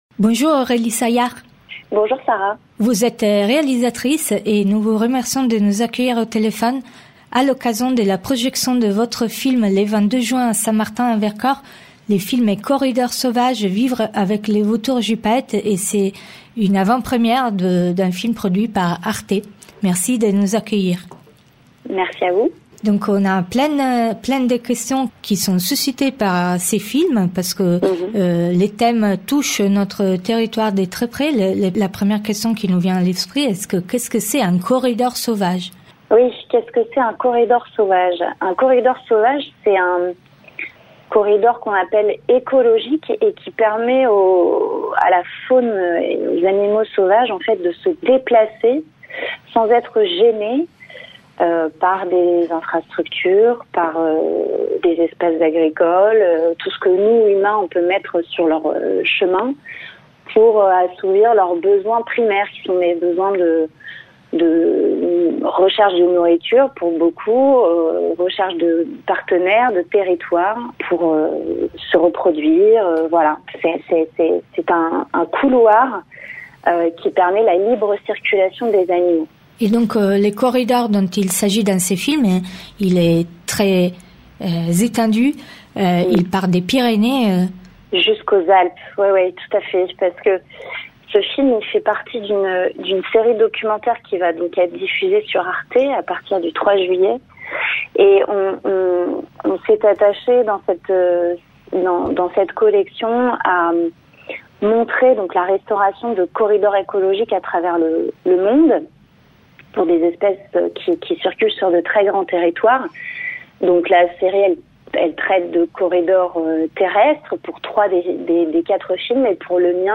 Nous avons interviewé